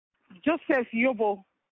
Joseph YOBOYósef Yobo